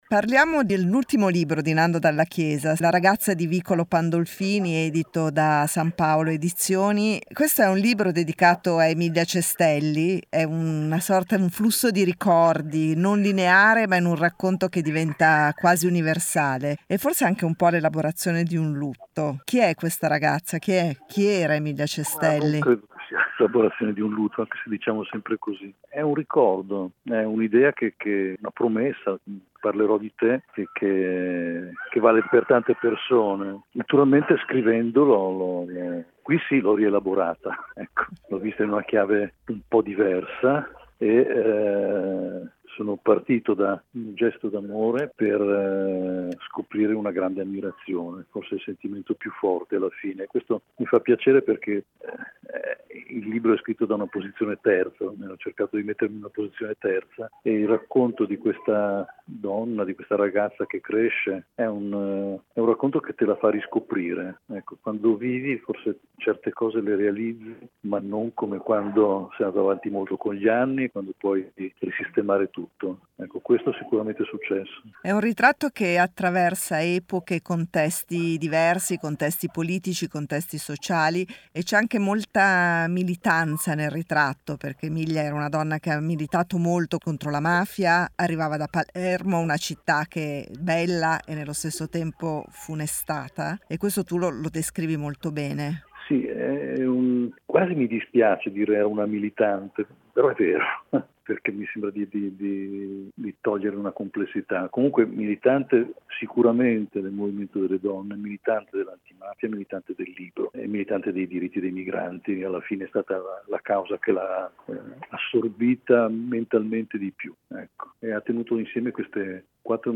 Una vita contro la mafia e per i diritti dei migranti. L'intervista a Nando dalla Chiesa